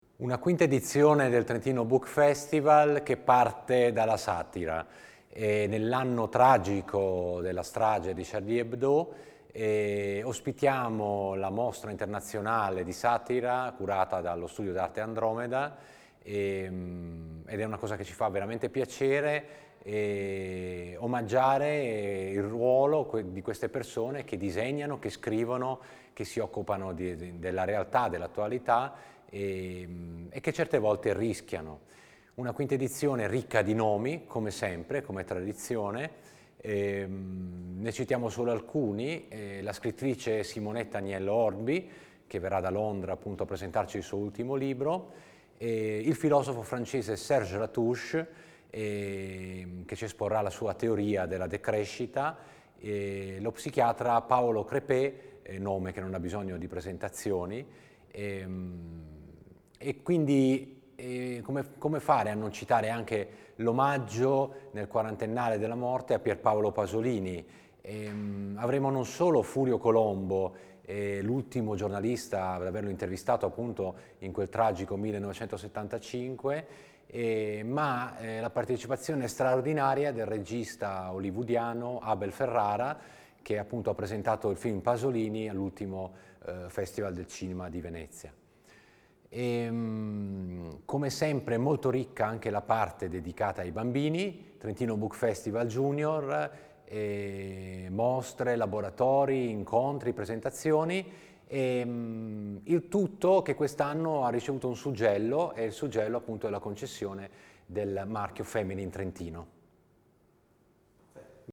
audiointervista